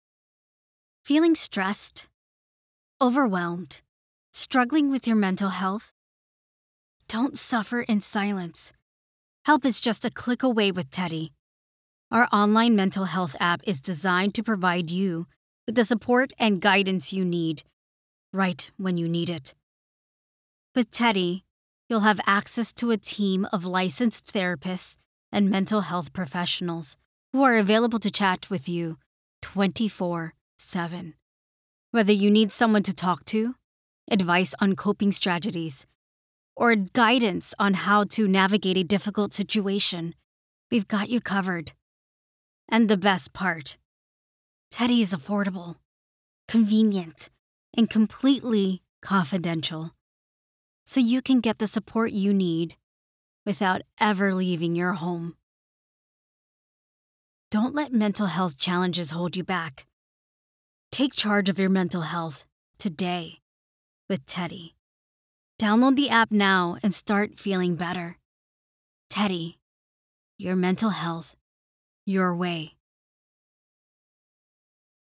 Female
Teenager (13-17), Yng Adult (18-29)
Smooth and versatile, my voice has a natural General American accent. It can reach both high and normal pitches with ease, while its velvety or clear tones add an extra layer of depth.
All our voice actors have professional broadcast quality recording studios.
0412Television_Ad-Clear_and_Convincing.mp3